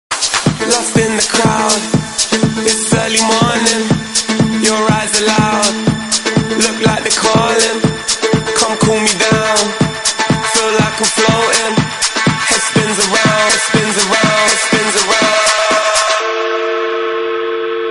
M4R铃声, MP3铃声, 欧美歌曲 111 首发日期：2018-05-14 12:08 星期一